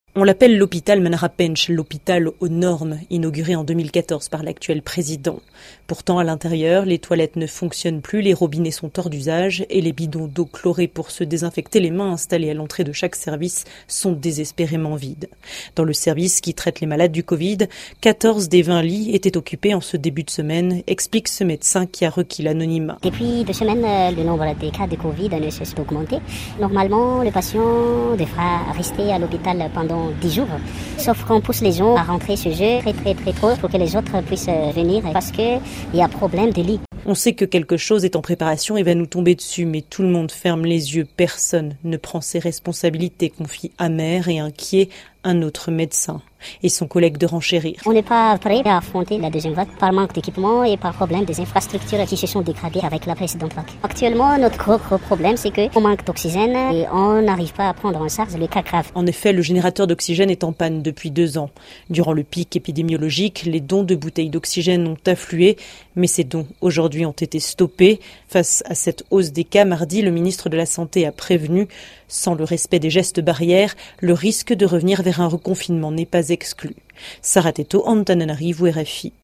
Selon ce reportage de RFI au CHU d’Andohatapenaka, les toilettes et les robinets ne fonctionnent plus, et les bouteilles d’oxygène sont vides.
reportage-hopital.mp3